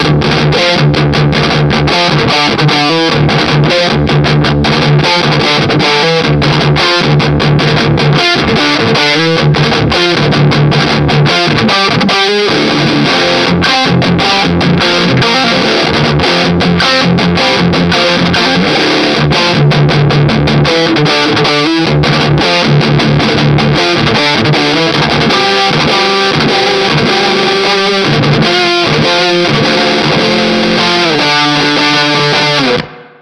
Capture of the Mesa Boogie Road King (version 1), the perfect road companion!